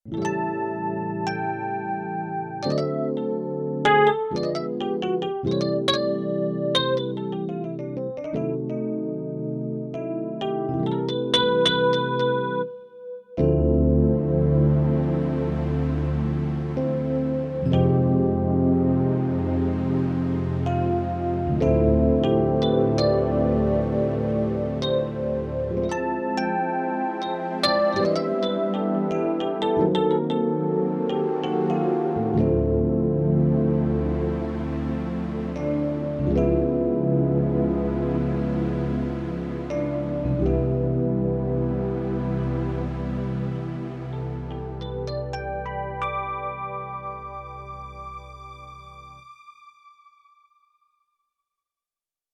Korg Radias audio demos
Factory sounds